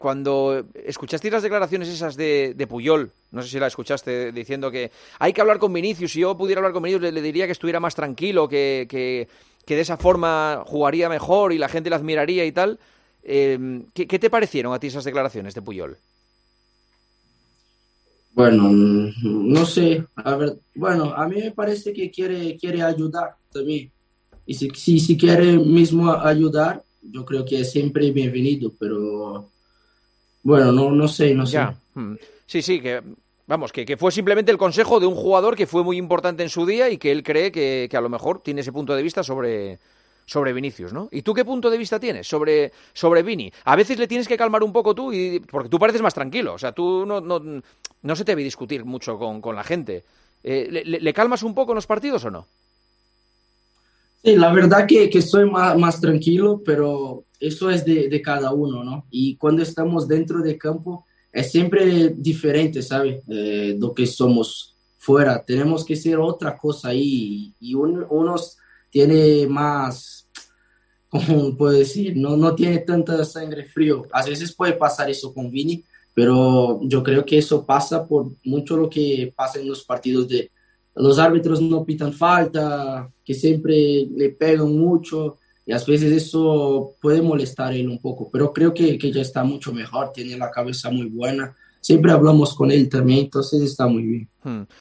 Juanma Castaño entrevista a Rodrygo Goes en El Partidazo de COPE
ESCUCHA LAS PALABRAS QUE RODRYGO LE DEDICA A VINICIUS CON JUANMA CASTAÑO EN EL PARTIDAZO DE COPE